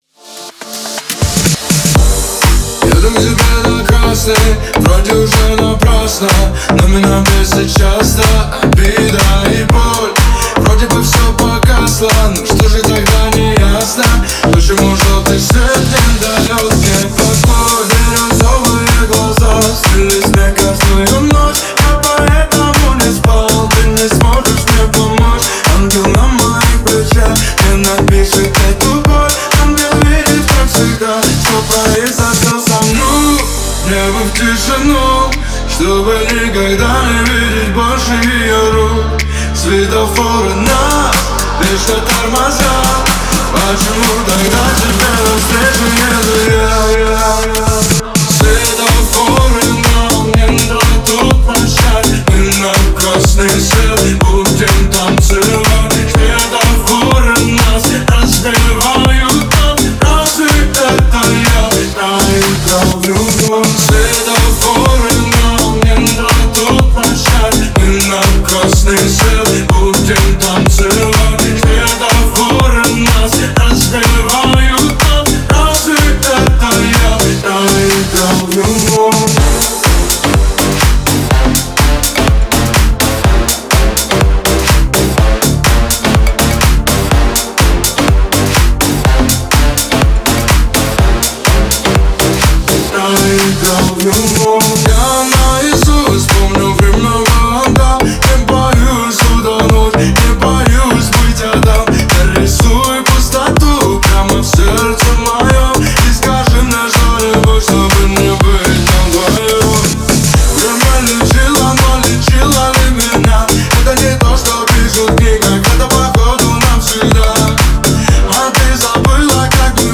это яркая и энергичная композиция в жанре поп